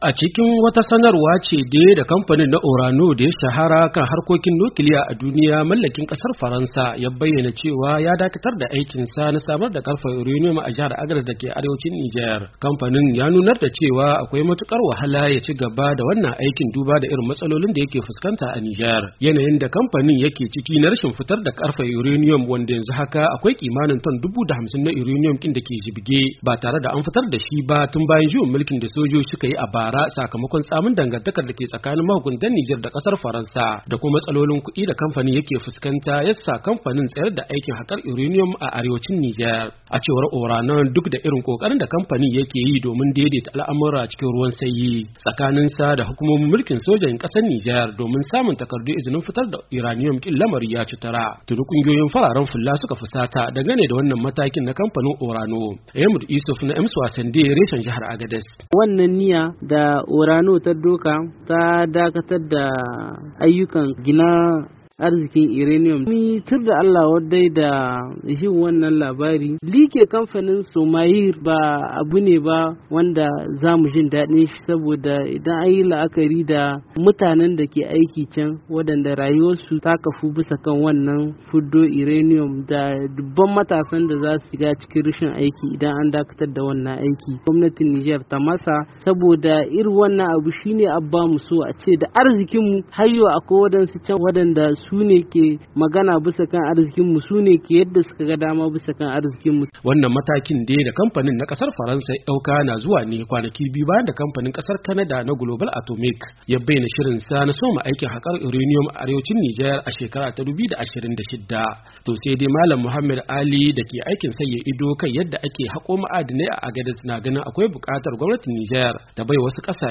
RAHOTON DAKATAR DA AIKIN KANPANIN ORANO